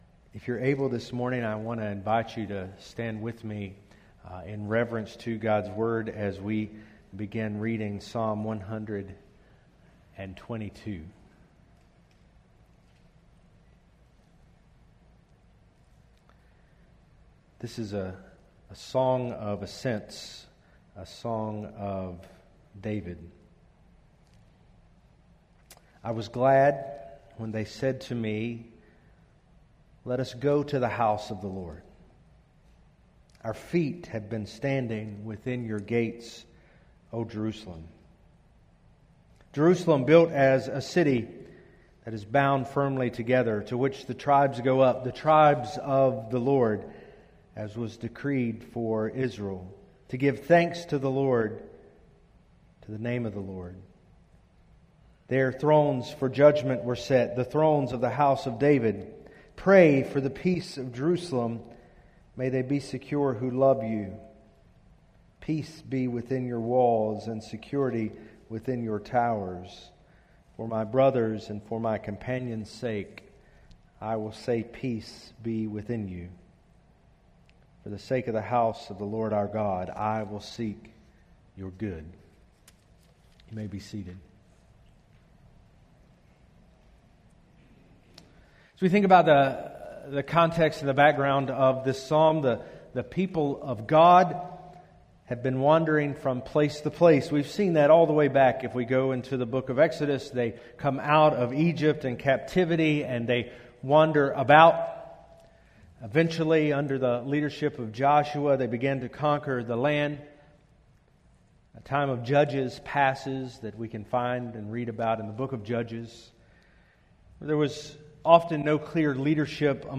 Play Rate Listened List Bookmark Get this podcast via API From The Podcast Enjoy sermons from the pulpit of First Baptist Icard, a Southern Baptist Church located in the foothills of North Carolina.